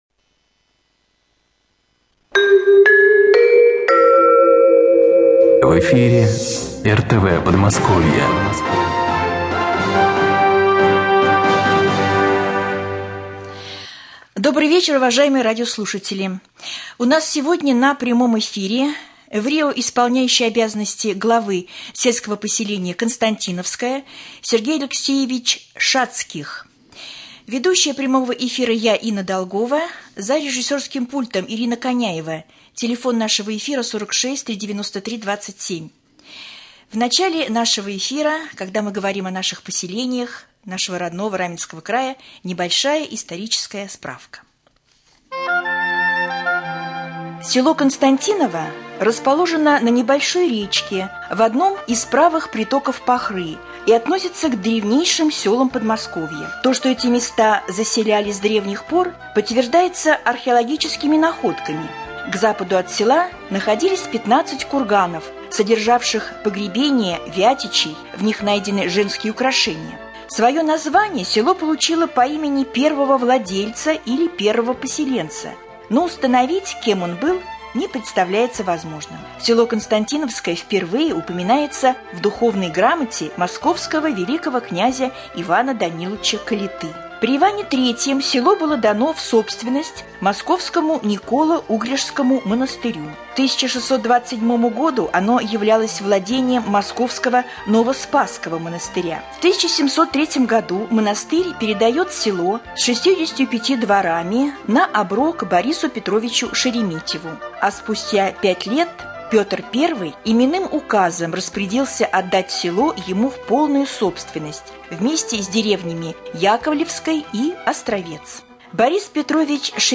Прямой эфир. Гость студии Врио Главы сельского поселения Константиновское Шацких Сергей Алексеевич.